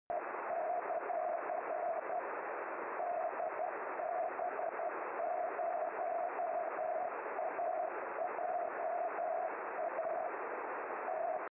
CW
Strong RS signal dir 135degree